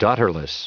Prononciation du mot daughterless en anglais (fichier audio)
Prononciation du mot : daughterless